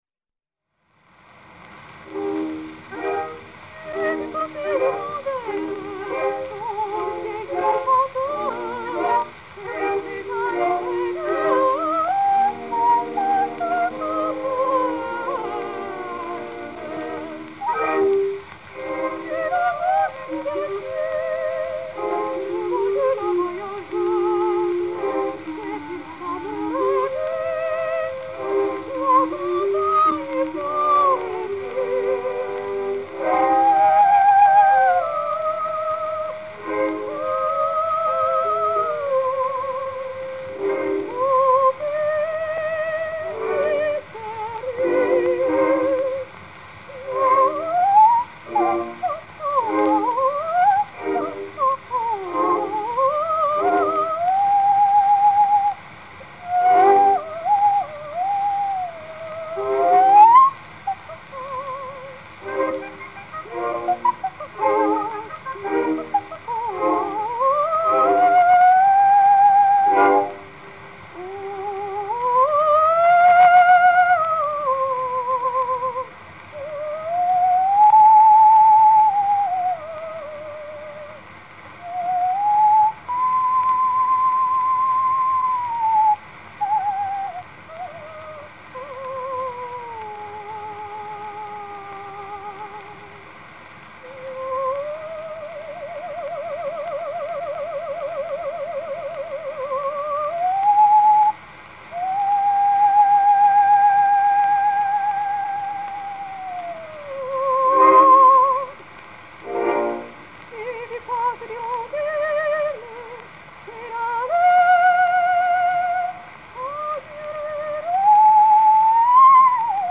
Sostituto della penultima ora, ma non da poco, perché tutte o quasi le cantanti coinvolte declinano (molte in chiave di soprano) il mito della grande primadonna con propensione a quella che oggi suole definirsi, con una punta di disprezzo, la baracconata.